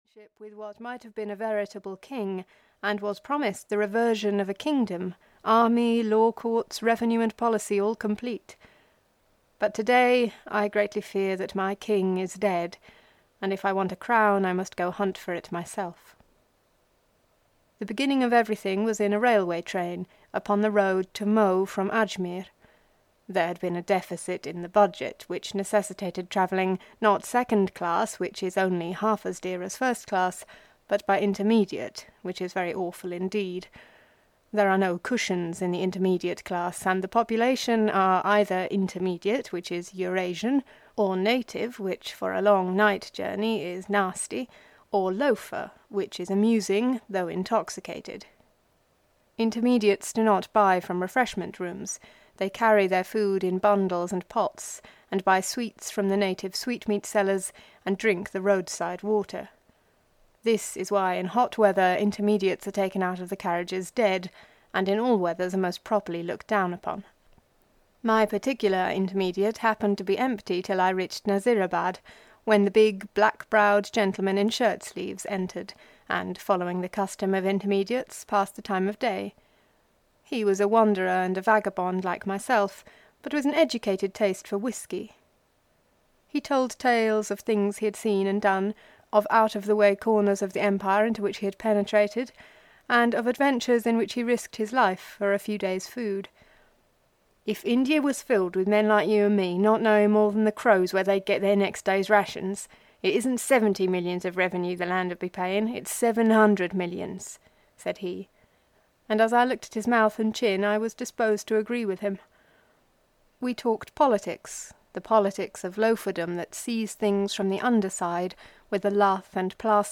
The Man Who Would Be King (EN) audiokniha
Ukázka z knihy